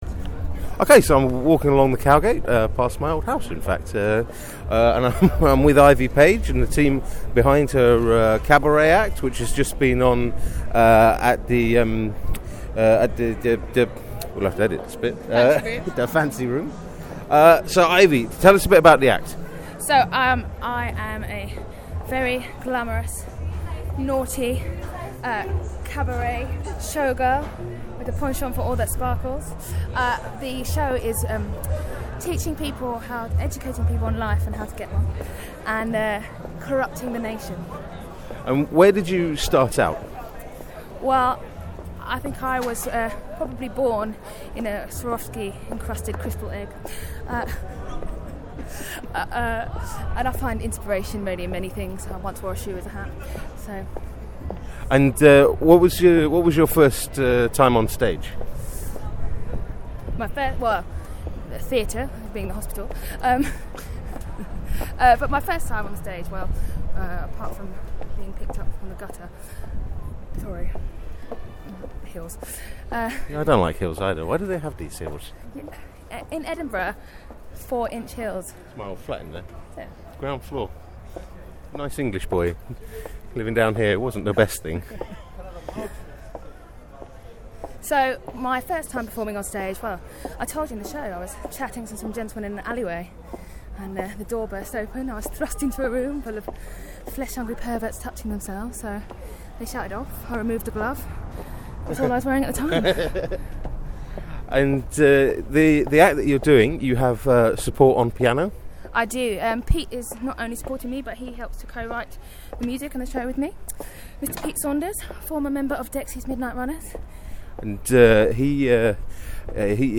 In a breathless interview
en route between engagements